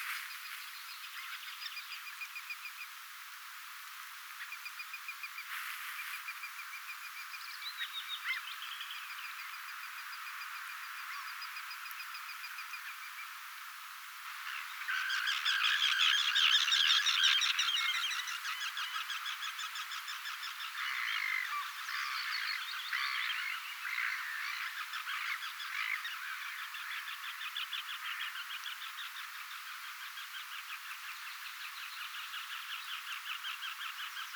lapintiirojen ääntelyä
Lapintiiroilla on monenlaista ääntelyä.
lapintiirojen_aantelya.mp3